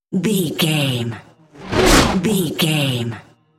Whoosh fast airy cinematic
Sound Effects
Atonal
Fast
futuristic
whoosh